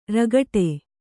♪ rākeṭ